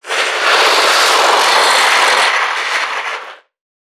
NPC_Creatures_Vocalisations_Infected [121].wav